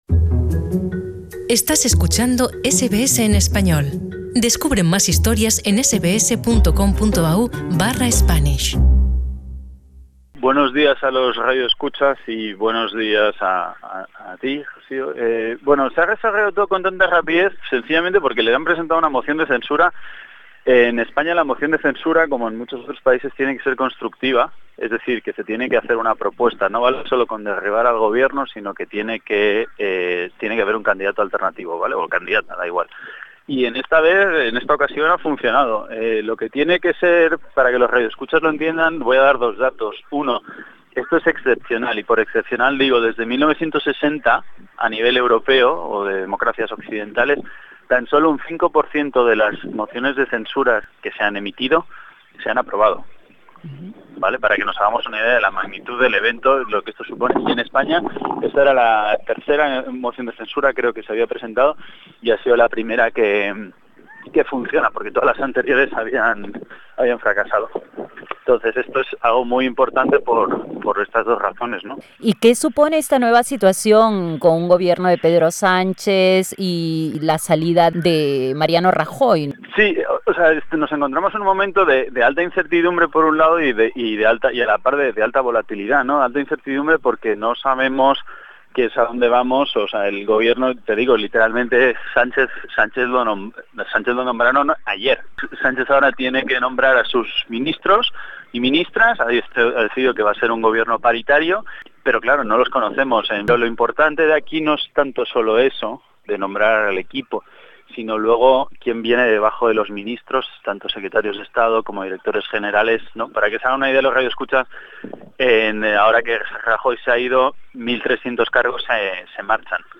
¿Cómo gobernará el PSOE y Pedro Sánchez a España en un ambiente político volátil e inestable? Conversamos con el analista político